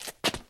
skatestart.ogg